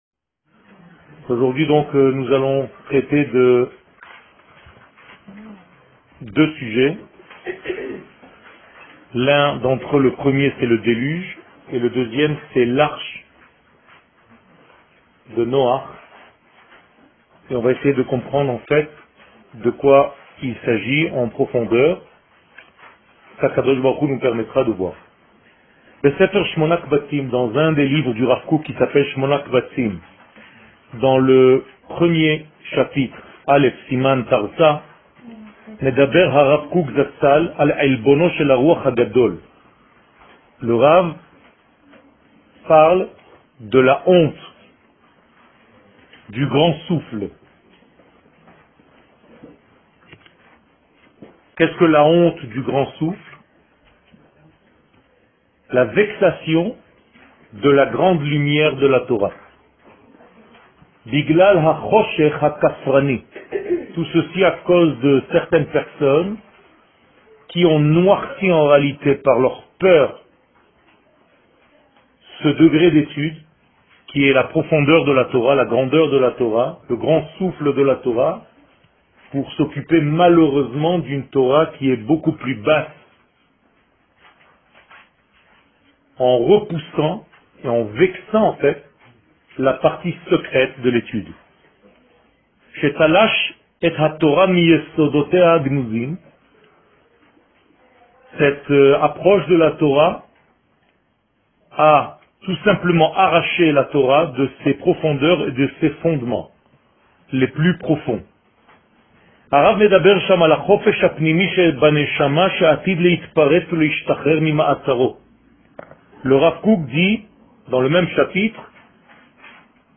Noah - L'arche et le secret du déluge Paracha שיעור מ 07 נובמבר 2016 01H 03MIN הורדה בקובץ אודיו MP3 (10.92 Mo) הורדה בקובץ אודיו M4A (7.57 Mo) TAGS : Parasha Torah et identite d'Israel שיעורים קצרים